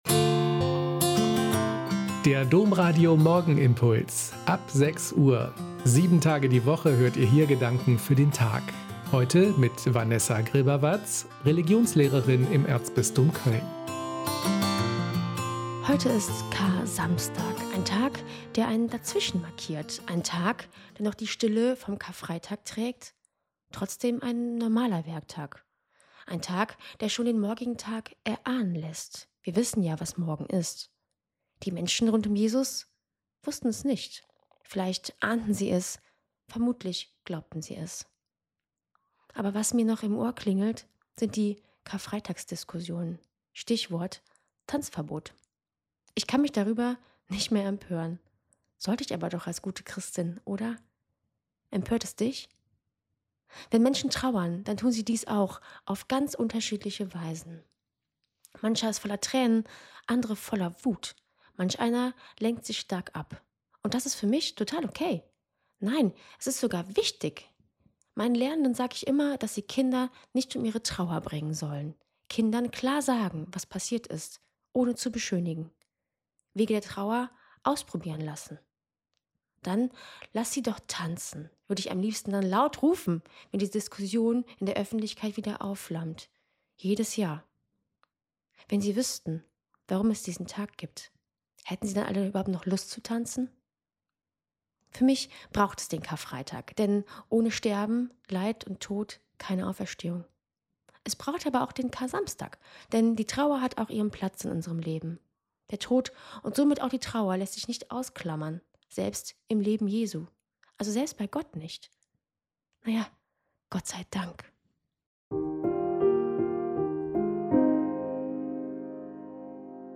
Morgenimpuls